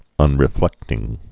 (ŭnrĭ-flĕktĭng)